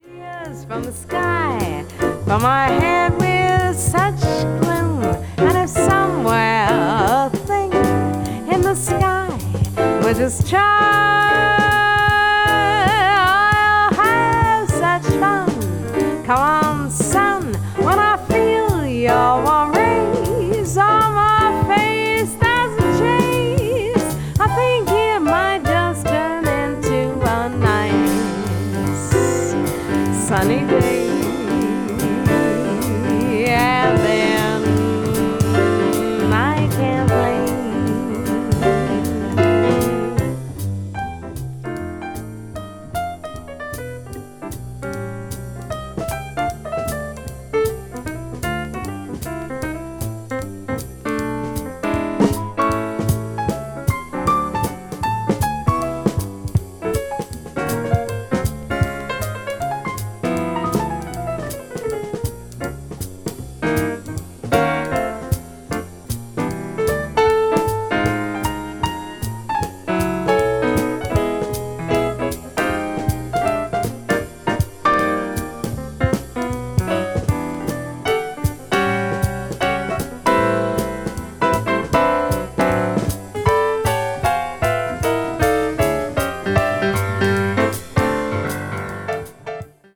jazz standard   jazz vocal   modern jazz   piano trio